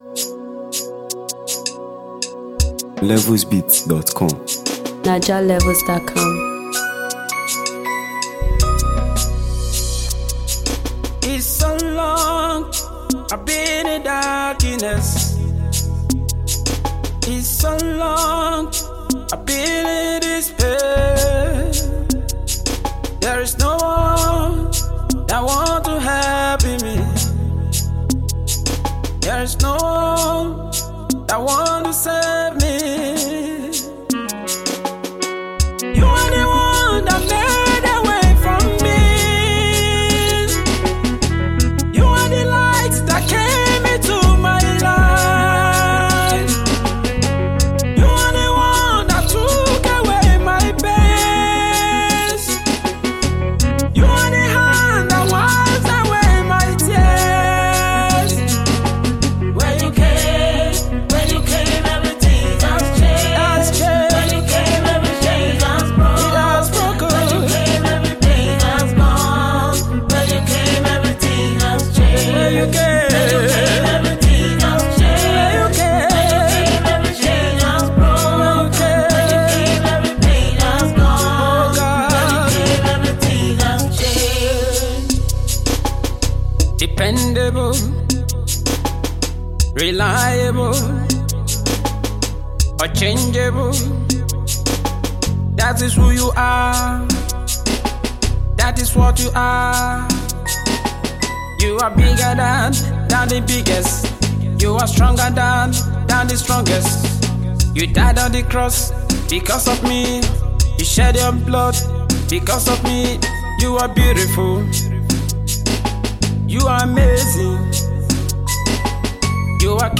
soulful and inspiring track